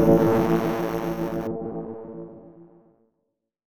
DroneScuff.wav